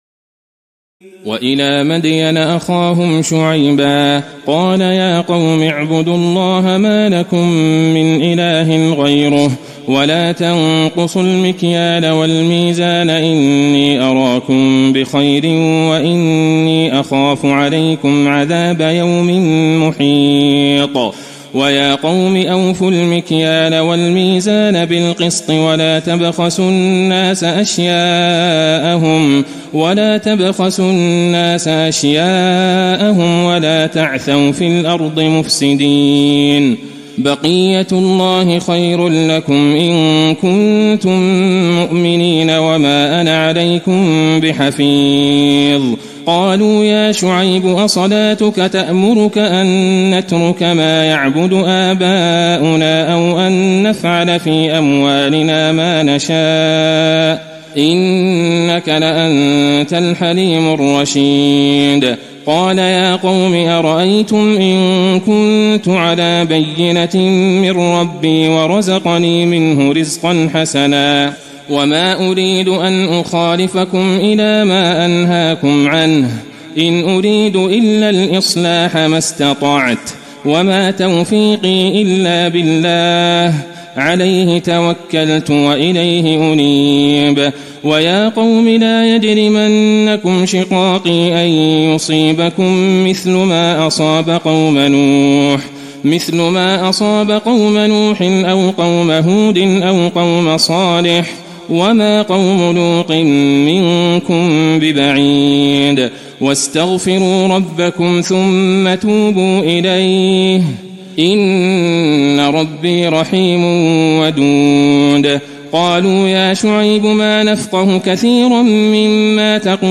تراويح الليلة الحادية عشر رمضان 1435هـ من سورتي هود (84-123) و يوسف (1-53) Taraweeh 11 st night Ramadan 1435H from Surah Hud and Yusuf > تراويح الحرم النبوي عام 1435 🕌 > التراويح - تلاوات الحرمين